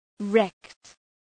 Shkrimi fonetik {rekt}
wrecked.mp3